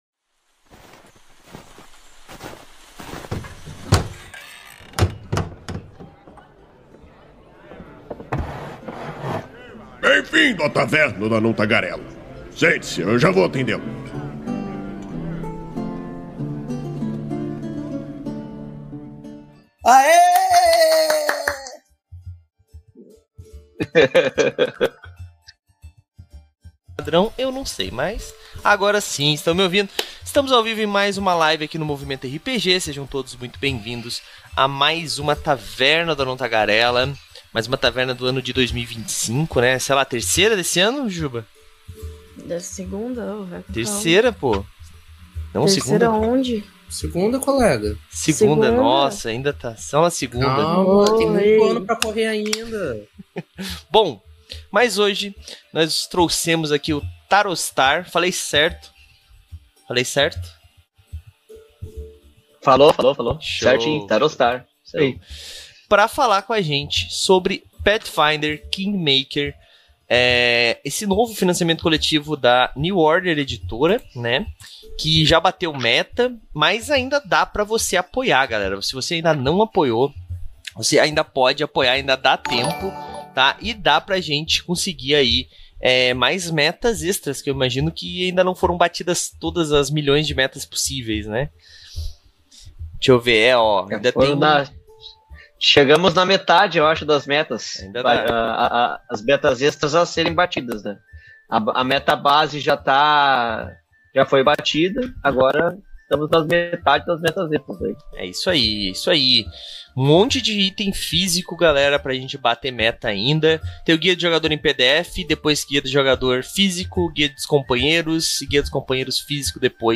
Venha saber mais sobre essa incrível aventura que te acompanha do nível 1 ao 20, bem como conhecer alguns detalhes doas NPCs dessa história e entender algumas mecânicas exclusivas desse livro. A Taverna do Anão Tagarela é uma iniciativa do site Movimento RPG, que vai ao ar ao vivo na Twitch toda a segunda-feira e posteriormente é convertida em Podcast.